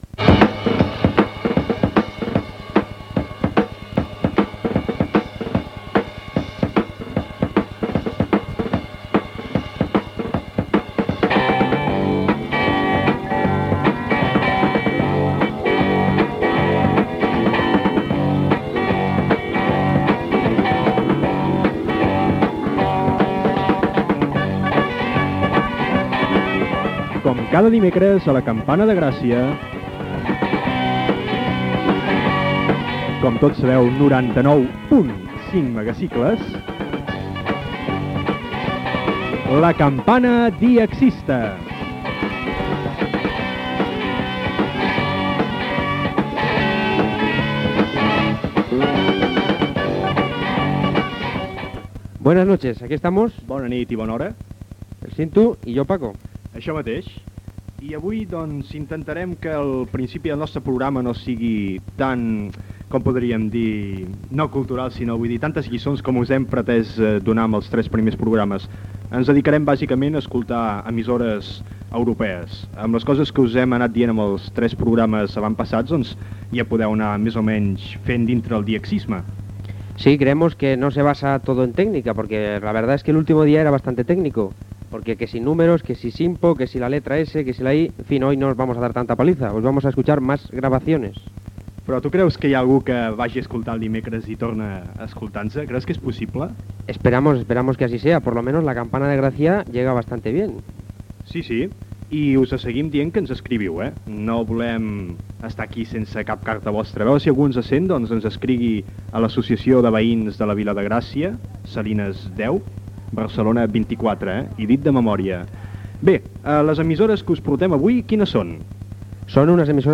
9b66020d25d5b84ac37ae7351f012640e3bcaaf9.mp3 Títol La Campana Emissora La Campana Titularitat Tercer sector Tercer sector Lliure Nom programa La campana dxista Descripció Presentació del programa i emissores internacionals europees fàcils d'escoltar en ona curta. Gènere radiofònic Divulgació
Banda FM